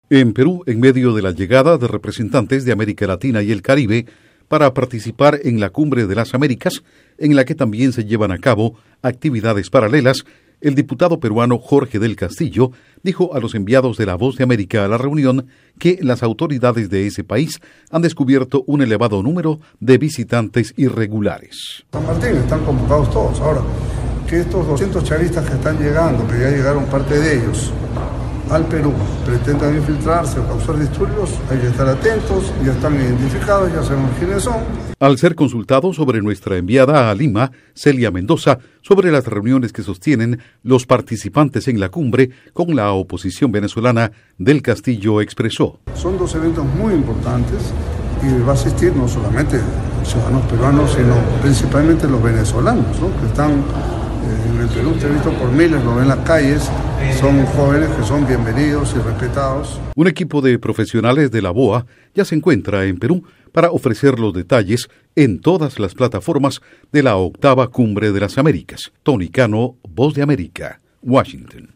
Con 2 audios de Jorge Del Castillo/Diputado de Perú.